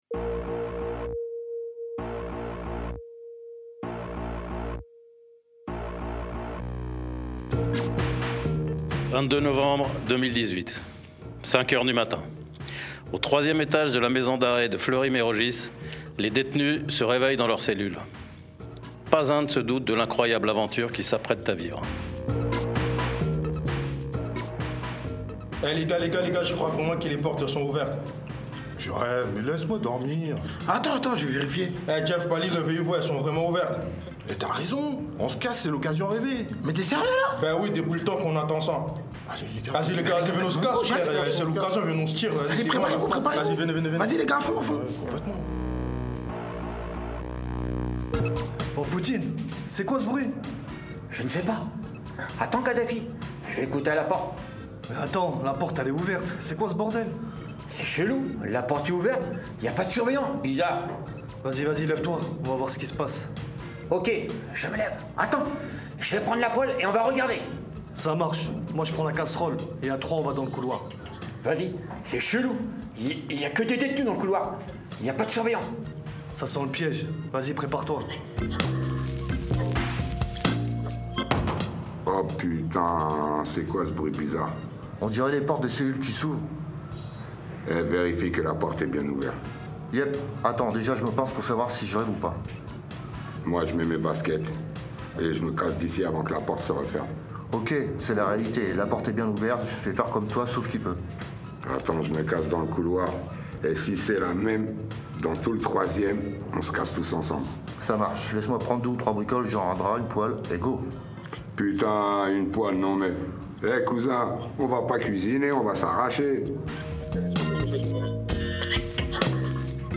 Atelier de création radiophonique, du 17 septembre au 15 novembre 2018 (12 séances de 2h) Animation des consignes d’écriture et création du récit, mise en
C’est à partir de cette courte histoire que nous élaborons dans un premier temps un scénario plus complet, pour ensuite créer une fiction sonore (d’une vingtaine de minutes), mélange de voix enregistrées, de bruitages et de musiques…